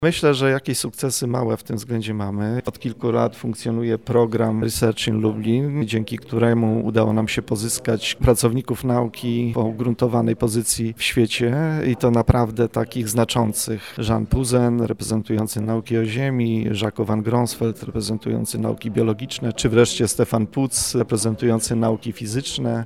Sprawa budżetu to jedno, ale stwarzanie przyjaznej atmosfery jest niemniej istotne – tak o przyciąganiu młodych naukowców mówi rektor UMCS prof. dr hab. Radosław Dobrowolski: